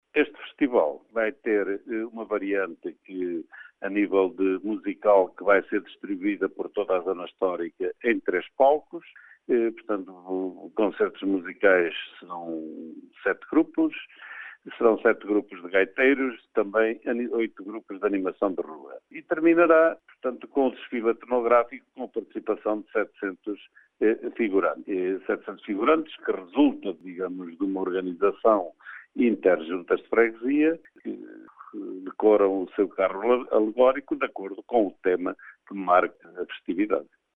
António Pimentel, presidente da câmara de Mogadouro, garante que o festival é de forte componente lúdica e cultural: